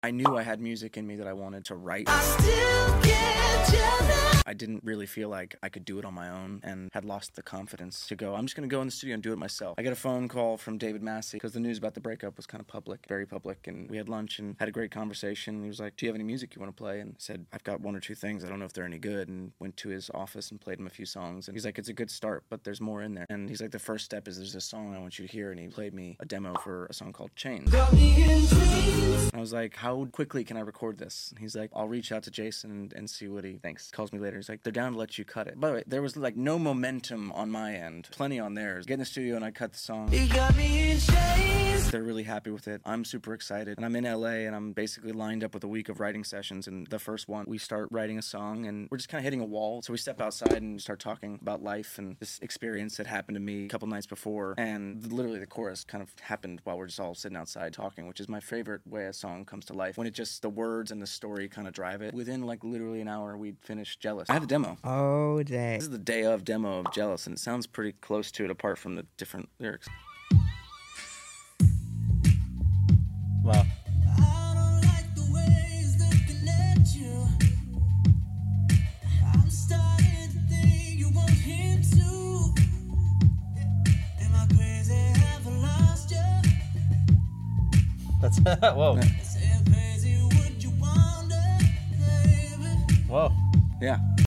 Nick Jonas Accidentally Made His Biggest Solo Songs Our full conversation